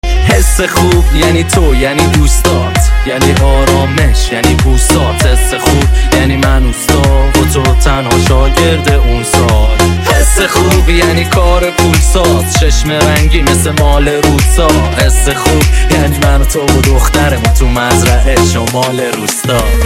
موزیک بیس دار
دموی ریمیکس ترکیبی